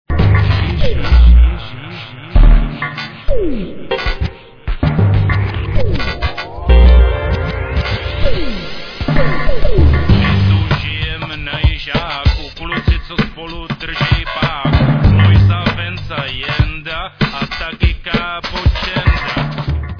hip-hopového singlu